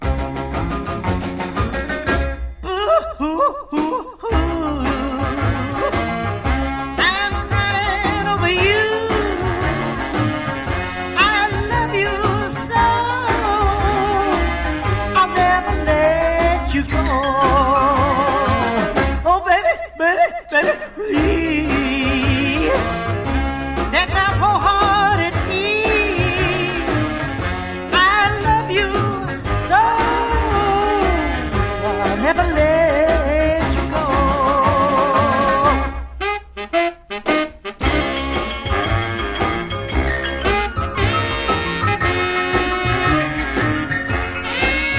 真っ白なウッドベースがトレードマークで、ここでは加えてギターとドブロ、スティールギターを演奏。
Rock'N'Roll, Rockabilly　Finland　12inchレコード　33rpm　Stereo